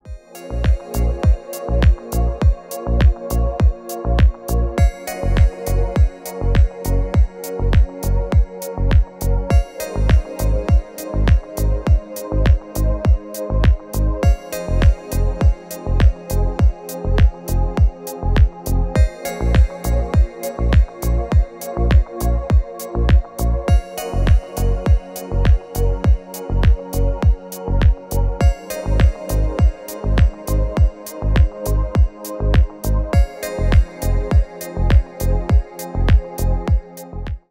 Поп Музыка
клубные # без слов